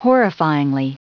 Prononciation du mot horrifyingly en anglais (fichier audio)
Prononciation du mot : horrifyingly